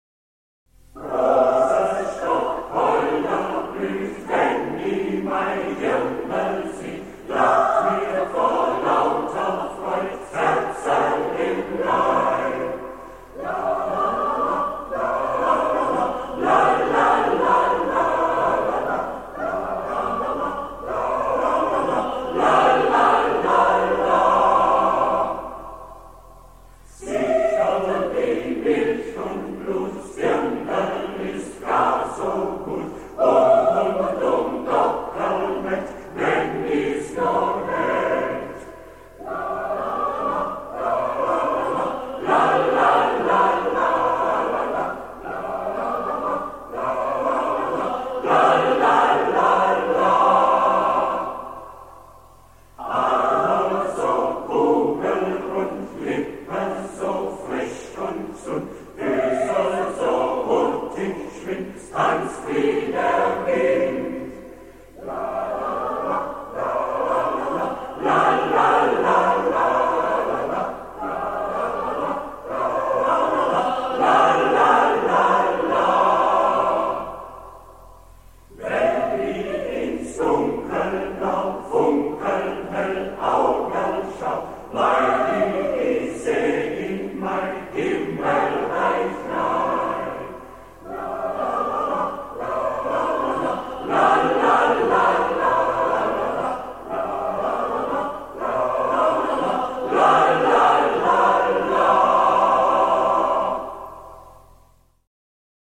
Chöre und Musikvereine des Stuttgarter Stadtteils wurden in das Studio Villa Berg eingeladen um den musikalischen Rahmen für die Sendung aufzunehmen.
Bei der Ausstrahlung der Sendung am 24.05.1975 saß sie mit ihrem Kassettenrecorder vor dem Radio und nahm die ganze Sendung auf.
Liederkranz Botnang mit der schwäbischen Volksweise "Rosestock, Holderblüh"